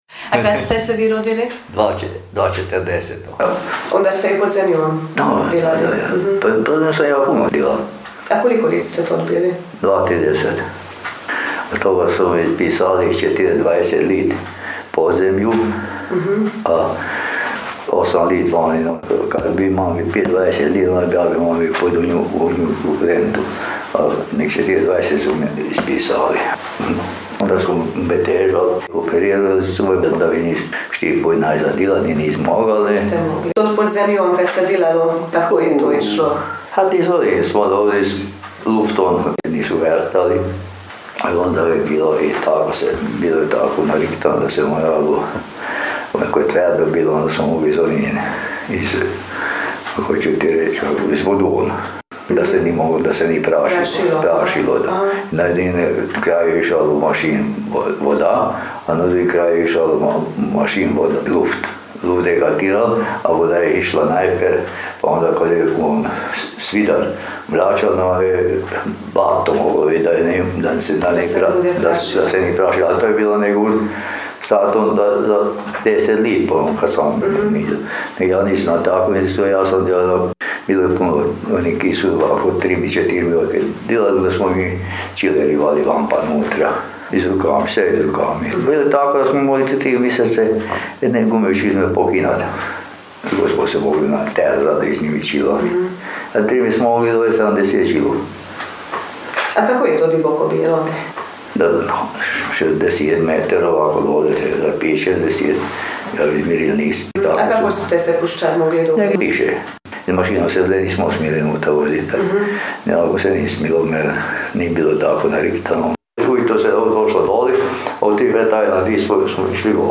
jezik naš, jezik naš gh dijalekti
Gornji Čatar – Govor